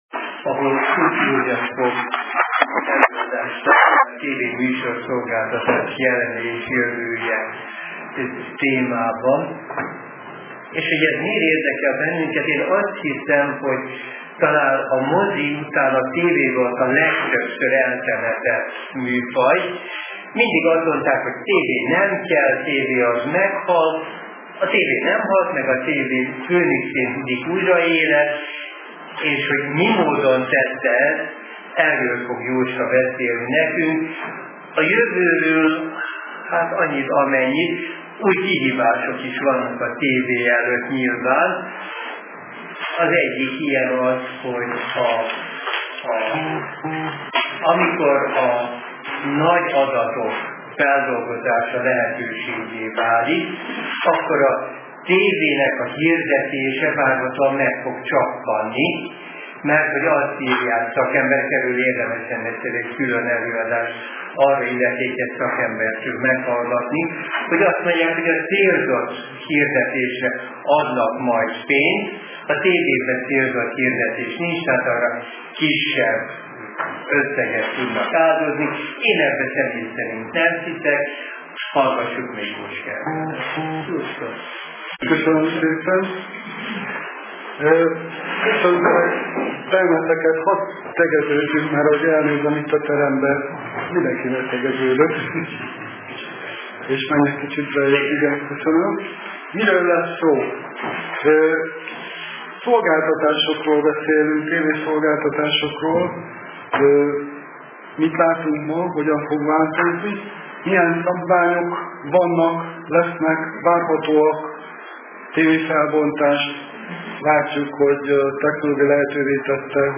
A Vételtechnikai és a Kábeltelevíziós Szakosztály valamint a Médiaklub meghívja az érdeklődőket az alábbi igen érdekes és időszerű előadásra